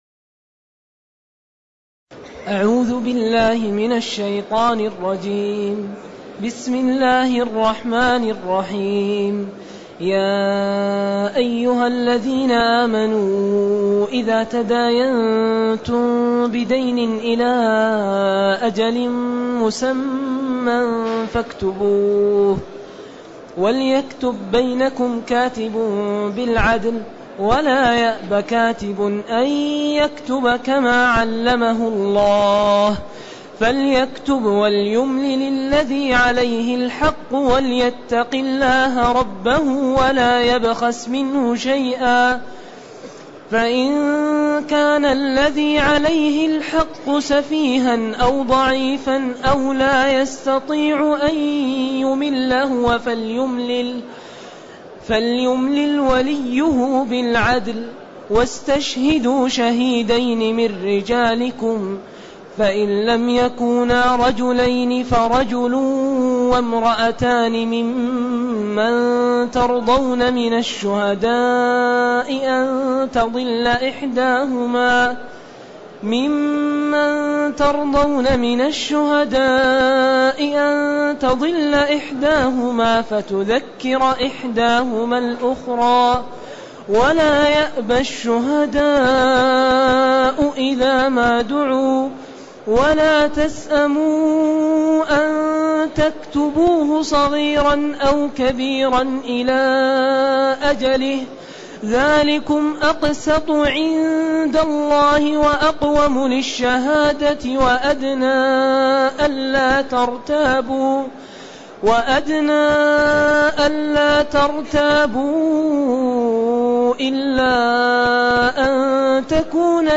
تاريخ النشر ٢ ذو الحجة ١٤٢٨ هـ المكان: المسجد النبوي الشيخ